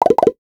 NOTIFICATION_Pop_13_mono.wav